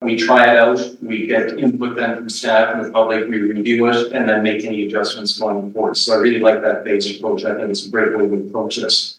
Councillor Gary Waterfield likes the way they’re handling this.